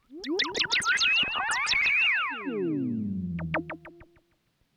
Birds Landing.wav